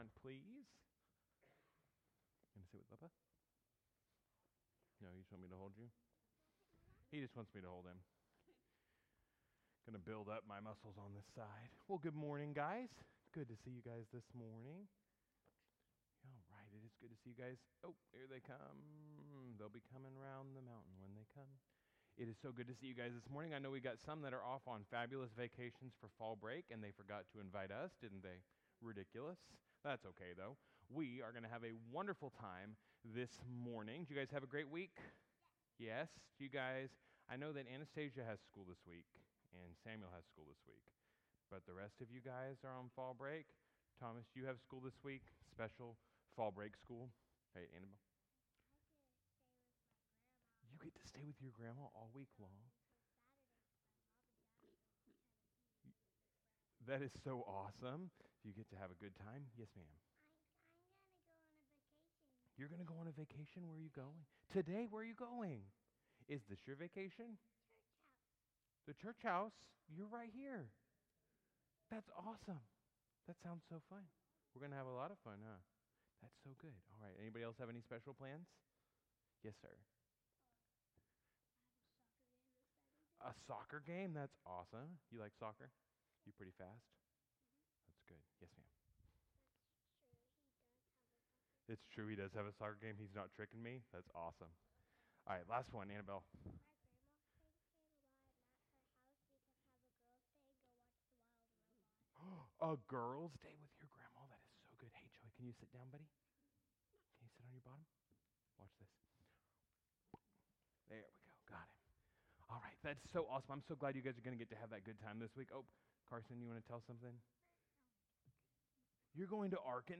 Alvin Missionary Baptist Church - Sunday Service